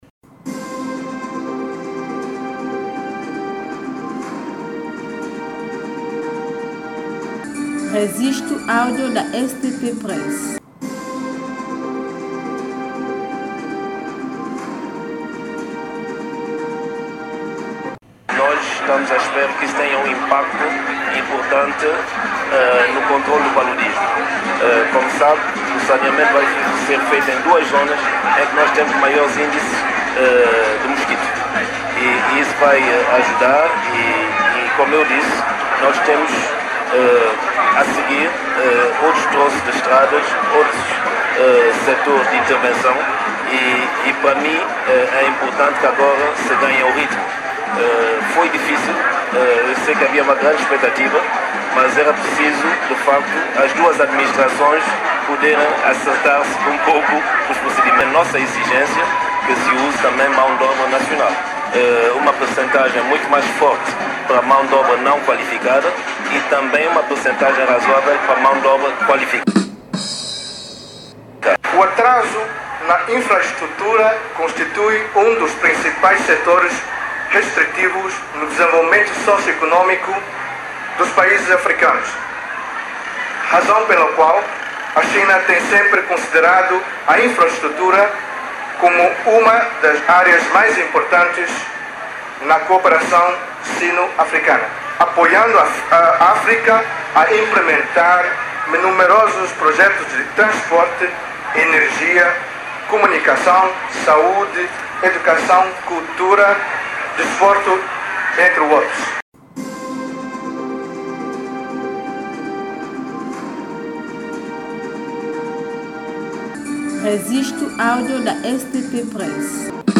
Durante a cerimónia de lançamento, o primeiro-ministro são-tomense, Patrice Trovoada e o embaixador chinês Wang Wei anunciaram que as obras surgem no quadro da cooperação específica virada para o sector das infraestruturas no âmbito do Acordo Geral da Cooperação entre os dois Países assinado em Abril de 2017 em Pequim, China.